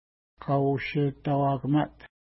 Image Not Available ID: 483 Longitude: -63.9513 Latitude: 53.7580 Pronunciation: ka:u:ʃeta:wa:kəma:t Translation: Esker Lake Feature: lake Explanation: A lengthy esker runs along the south shore of the lake.